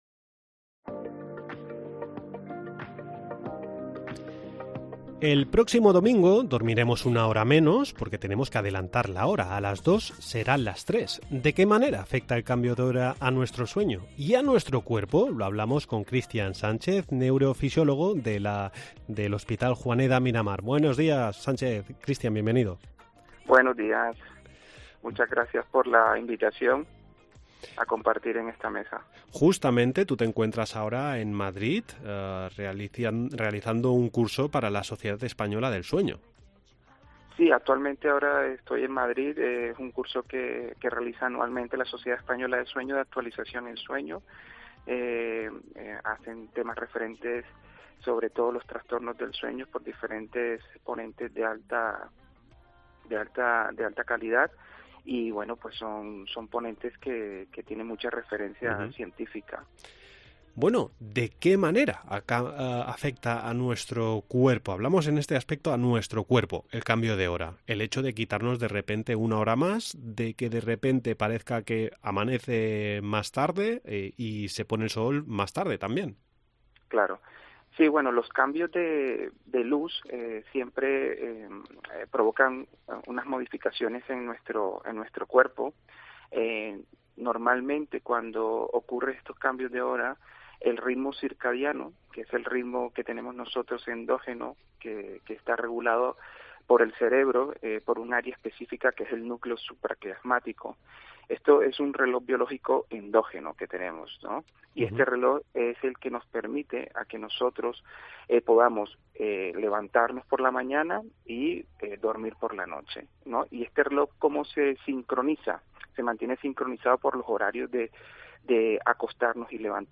¿De qué manera afecta el cambio de hora a nuestro cuerpo? Lo hablamos con